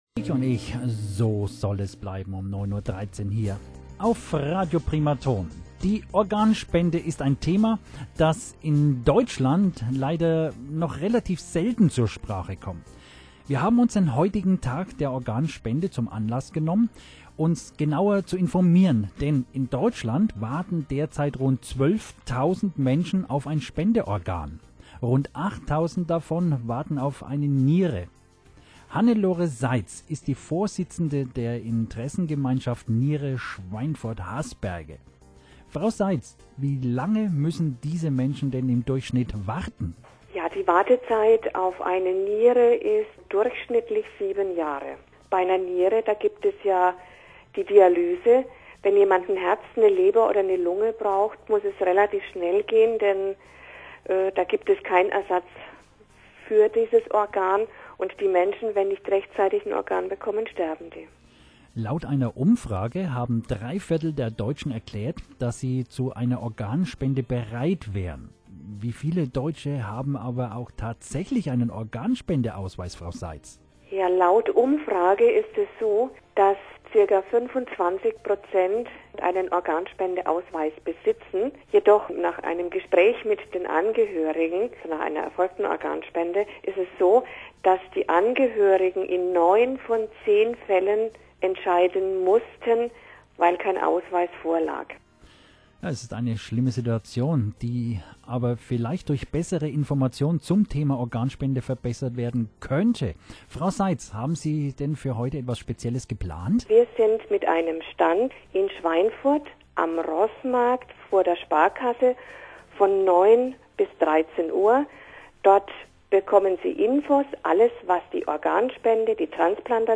Interview am Tag der Organspende zum neuen Transplantationsgesetz - Teil 1